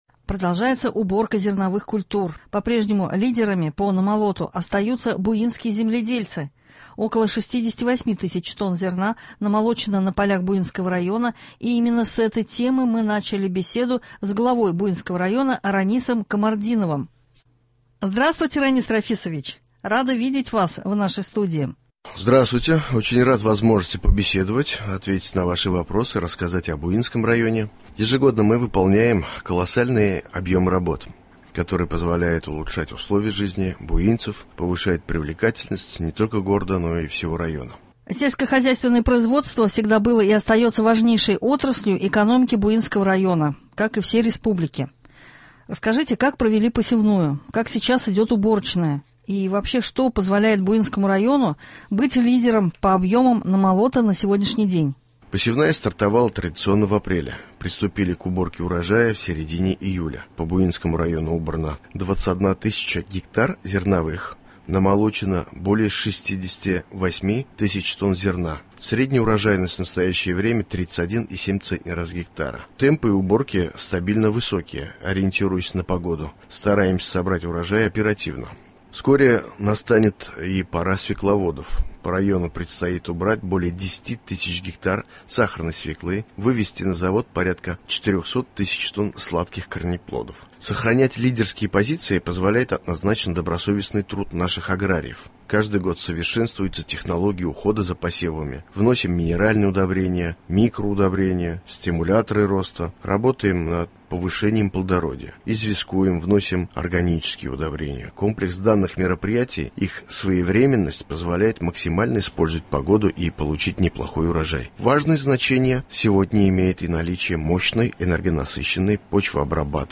Что позволило Буинскому району стать лидером по объемам намолота на сегодняшний день? Рассказывает глава Буинского муниципального района Ранис Камартдинов.